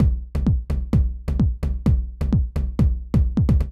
INT Beat - Mix 11.wav